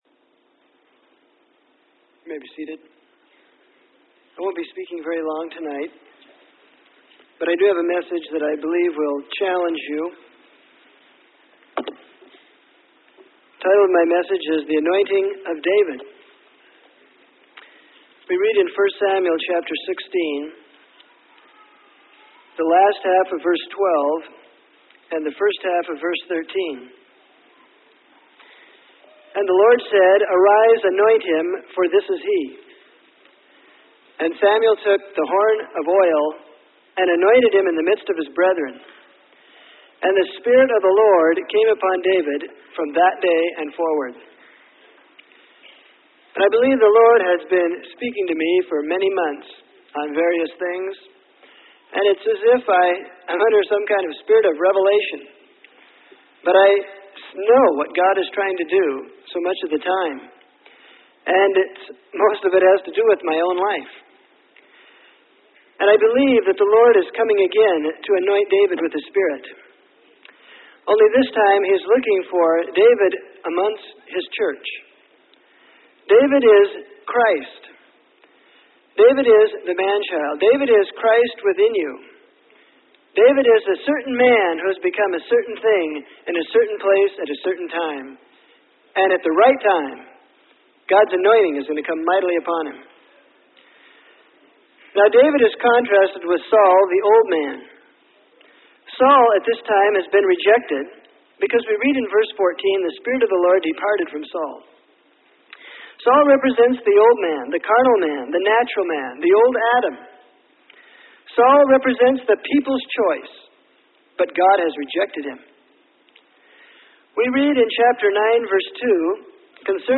Sermon: The Anointing Of David.